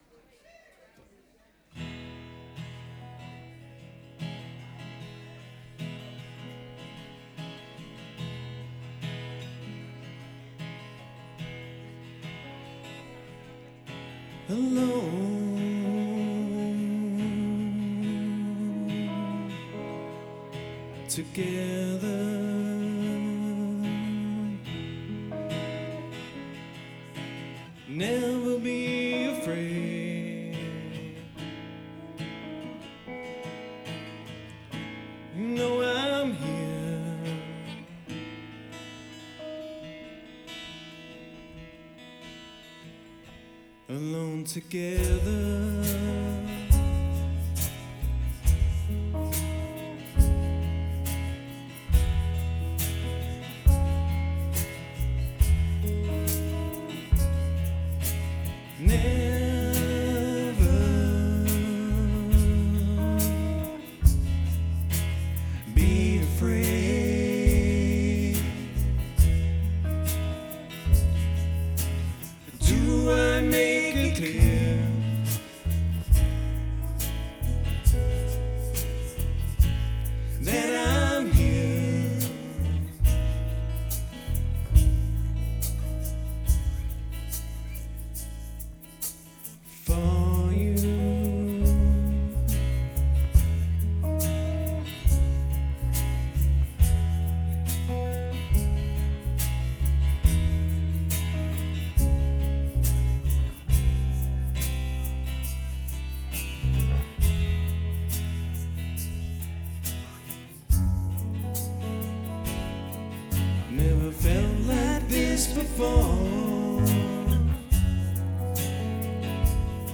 POMME D'OR CONCERT